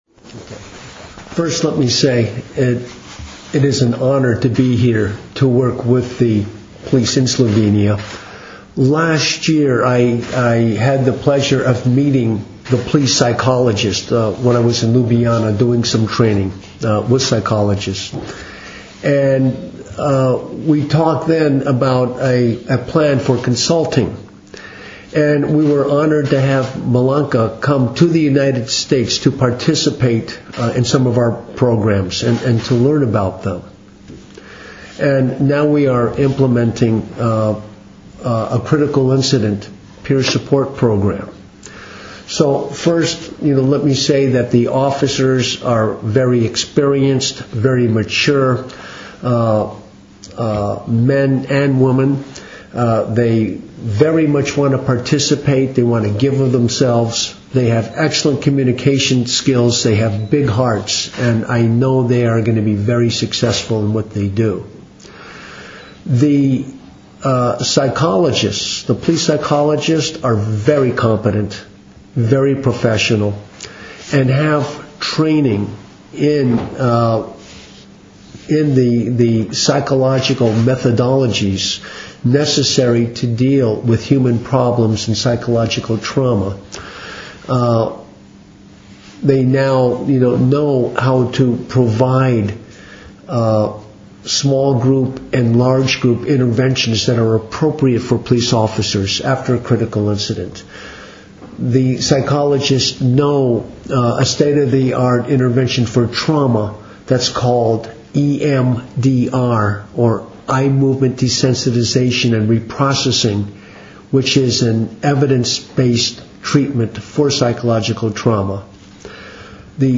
Policija - Psihološka pomoč in zaščita policistov - informacija z novinarske konference